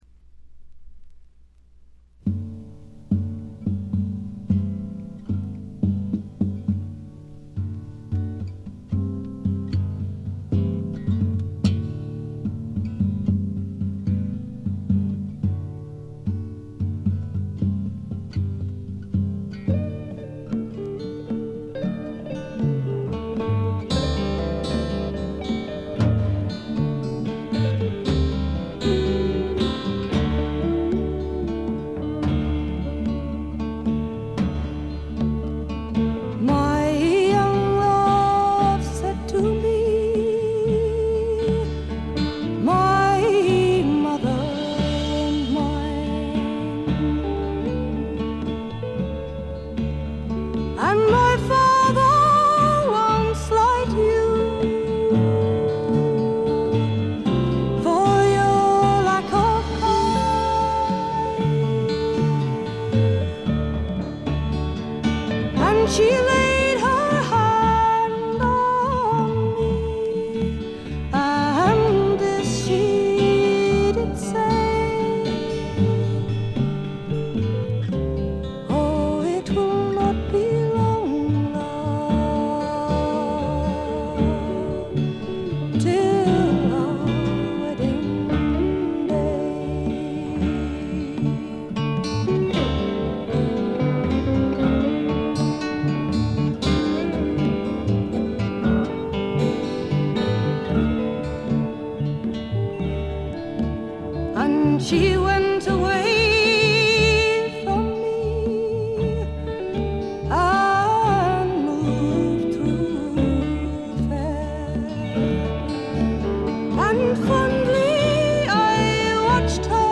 極めて良好に鑑賞できます。
英国フォークロック基本中の基本。
試聴曲は現品からの取り込み音源です。